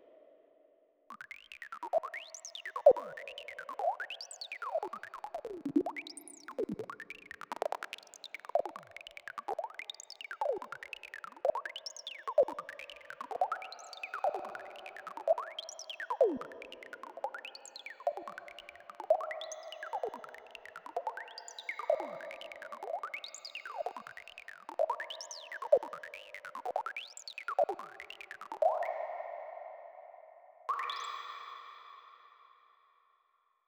145-E-Saw-Arp.wav